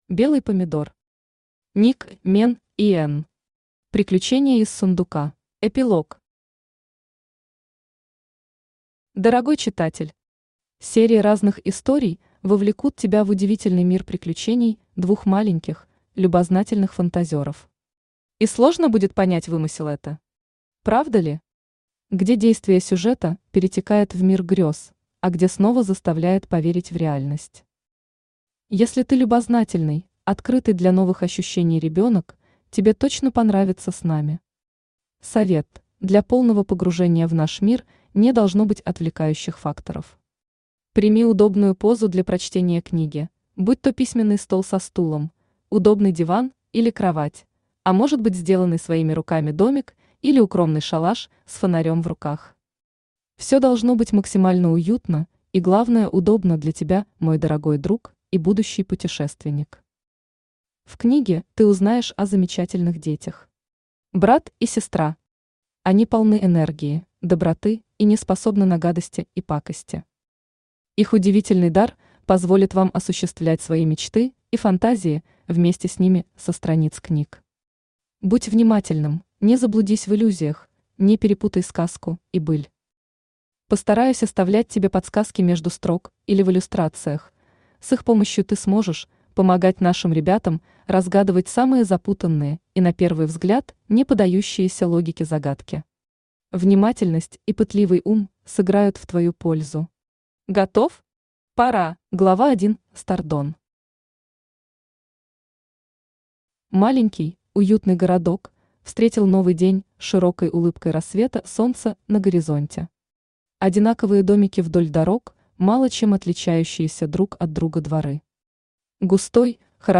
Аудиокнига Ник (Мен) и Энн. Приключения из сундука | Библиотека аудиокниг
Приключения из сундука Автор Белый Помидор Читает аудиокнигу Авточтец ЛитРес.